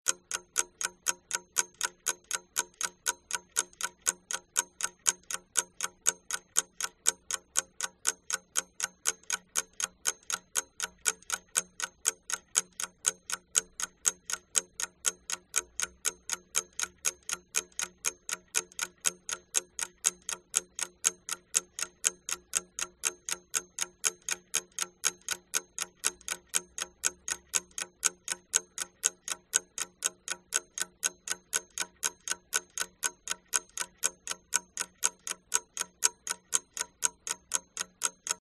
Звуки часов с кукушкой
Громкий тик-так